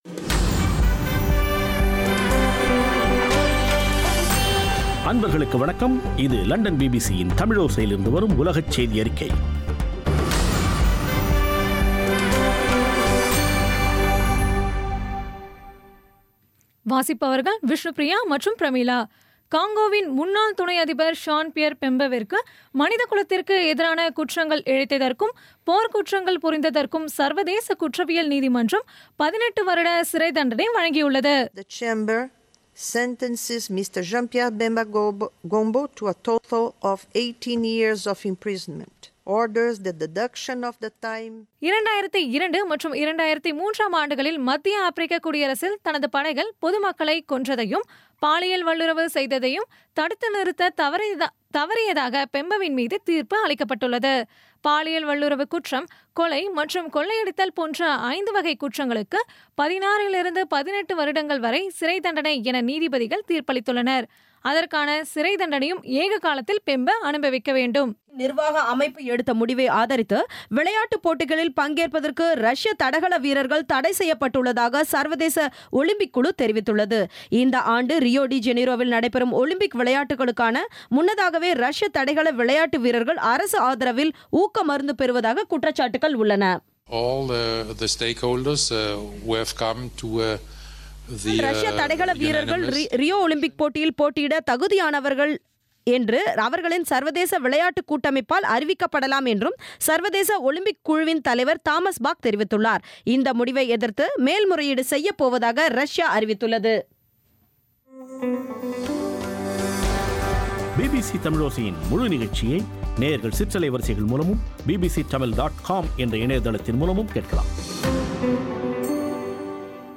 இன்றைய பிபிசி தமிழோசை செய்தியறிக்கை – 21.06.2016